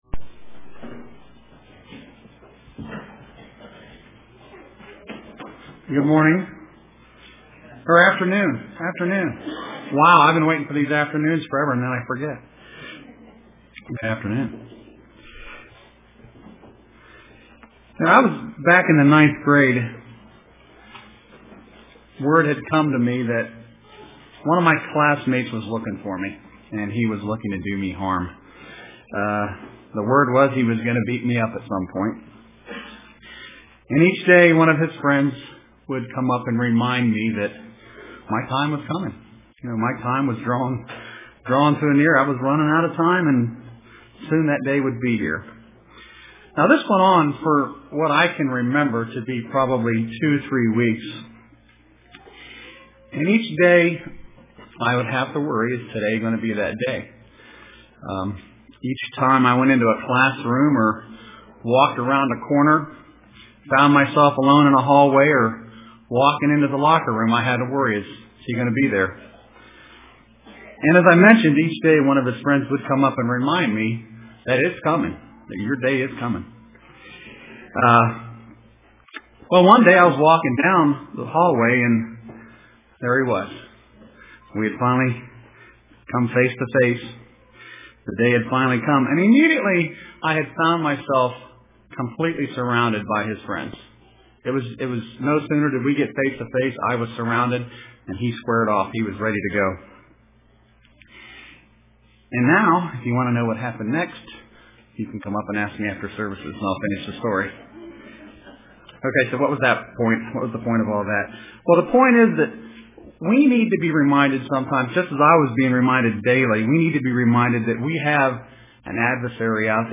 Print Know the Spirits UCG Sermon